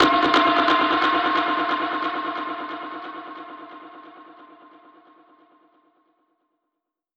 Index of /musicradar/dub-percussion-samples/134bpm
DPFX_PercHit_C_134-12.wav